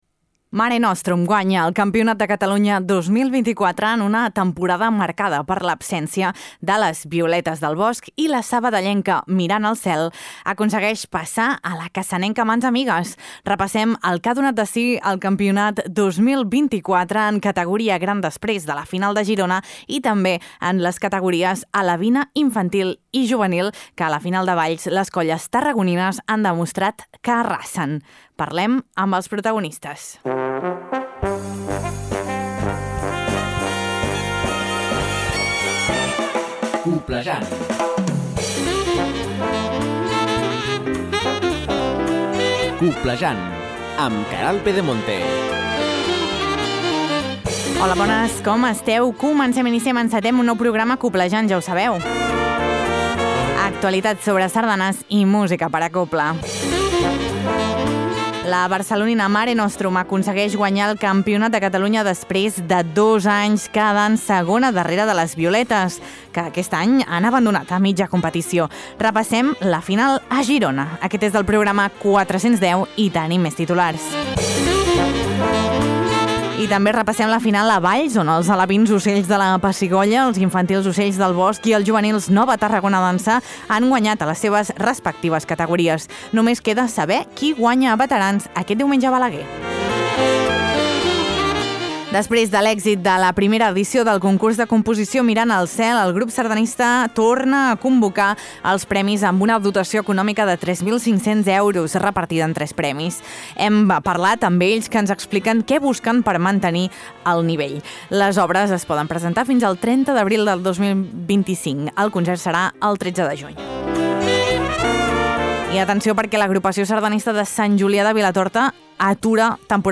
Tot això i molt més a Coblejant, un magazín de Ràdio Calella Televisió amb l’Agrupació Sardanista de Calella i en coproducció amb La Xarxa de Comunicació Local que s’emet per 75 emissores a tots els Països Catalans. T’informa de tot allò que és notícia al món immens de la sardana i la cobla.